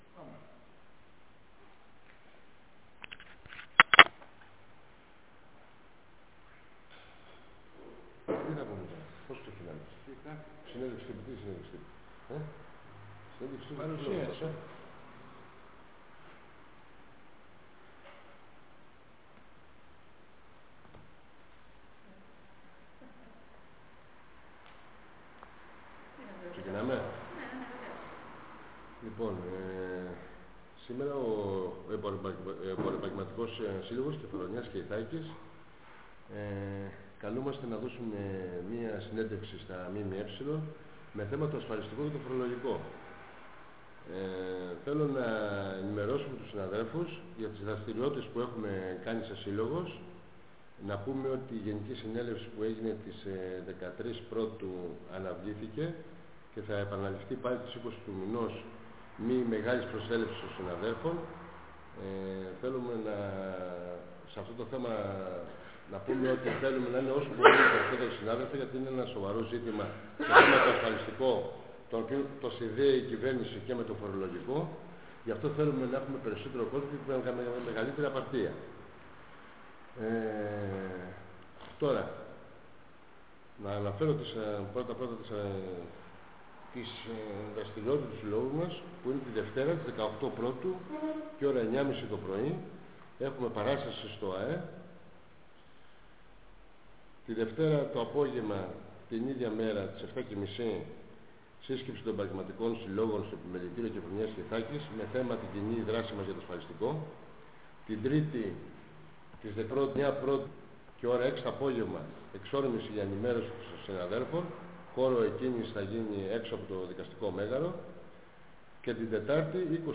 Συνέντεξη τύπου δόθηκε από τον Εμποροεπαγγελματικό Σύλλογο για το αντιασφαλιστικό νομοσχέδιο της κυβέρνησης.